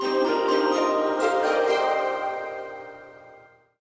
enchanted_forest_loading_01.ogg